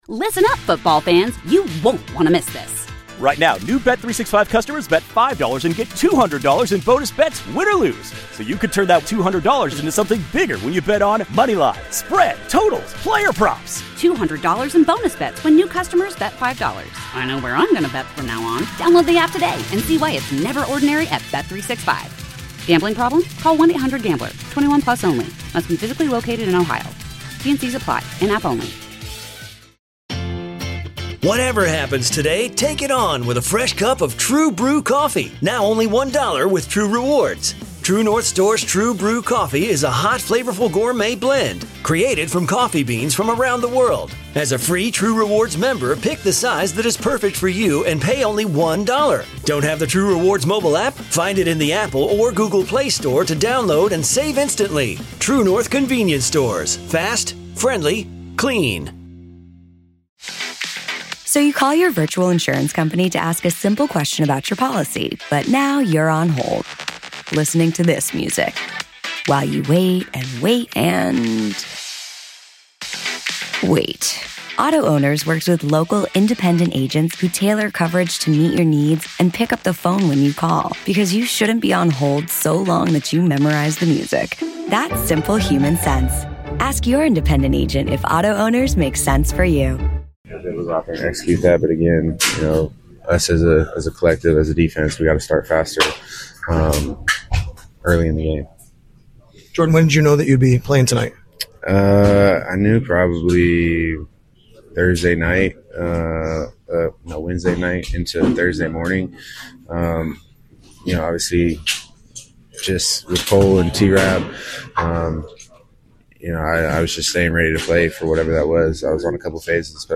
10-13 Jordan Poyer Postgame